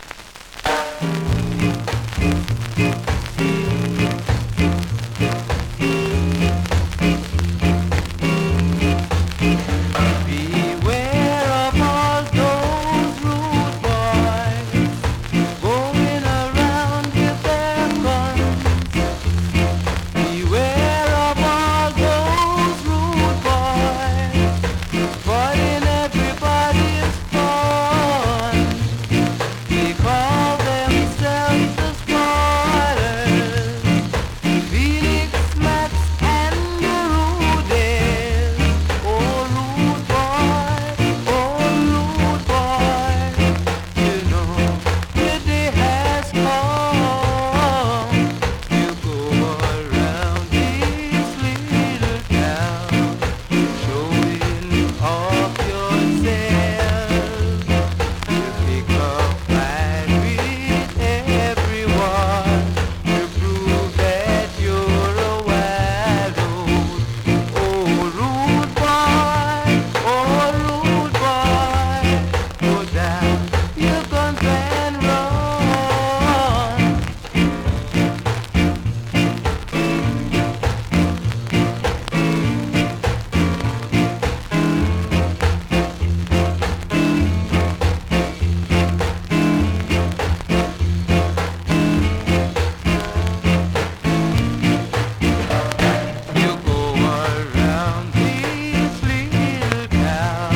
ROCKSTEADY
スリキズ、ノイズそこそこあります。